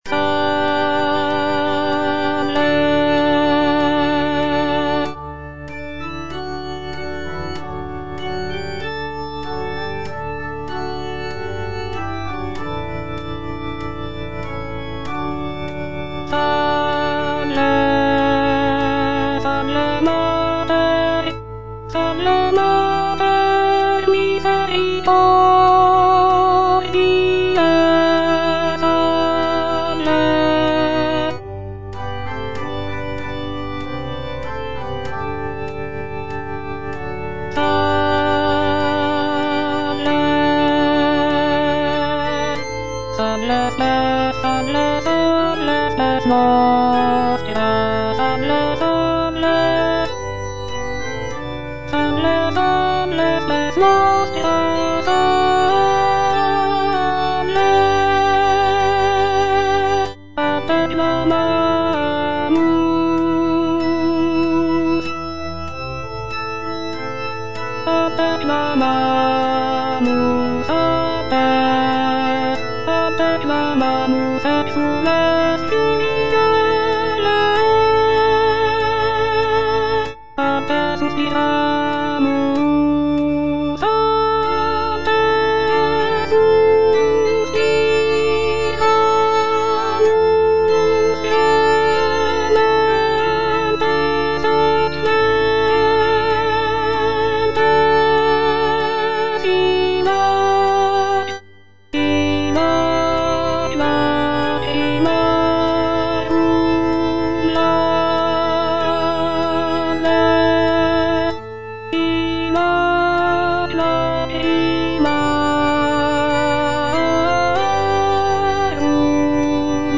G.F. SANCES - SALVE, REGINA Alto (Voice with metronome) Ads stop: auto-stop
"Salve, Regina" by Giovanni Felice Sances is a sacred vocal work written in the 17th century.